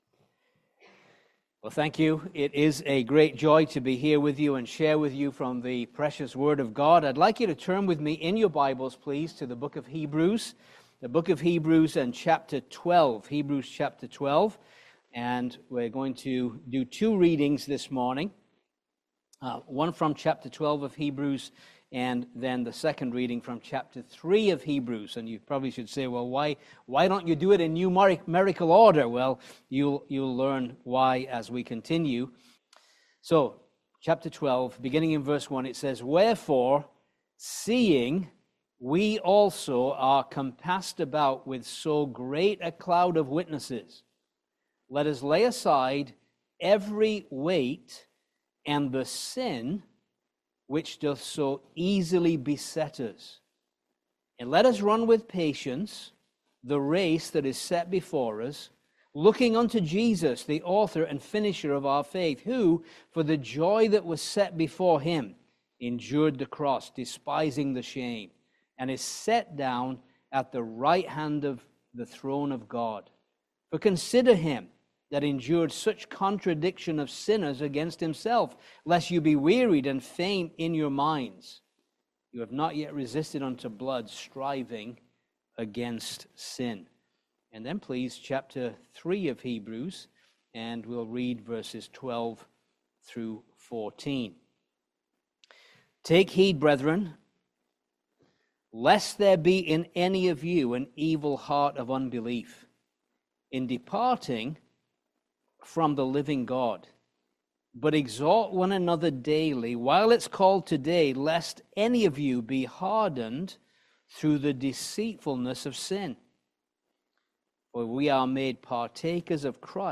Hebrews 12:1-4 Service Type: Family Bible Hour Sin is unbelief